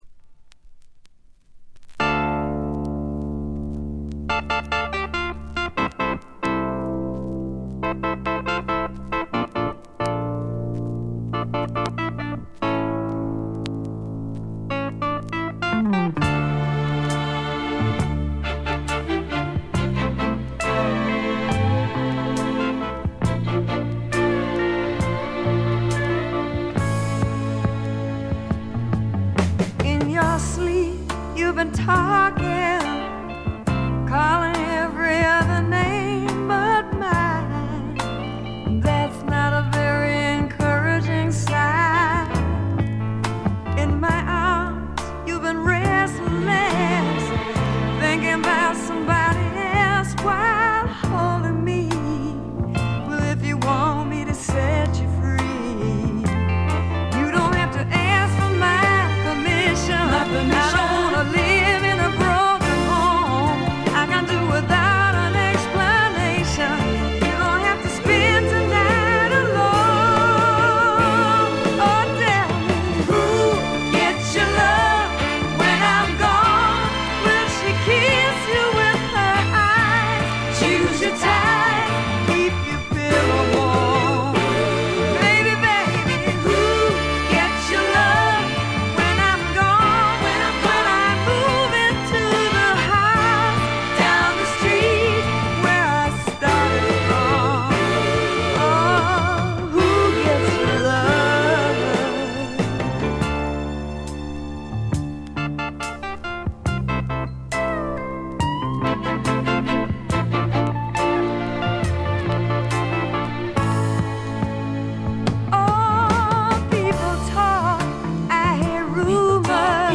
A1冒頭プチ音、B4フェードアウト部周回気味ノイズ。大きなノイズはありません。
フリー・ソウル系のレアグルーヴものとしても高人気の一枚です。
試聴曲は現品からの取り込み音源です。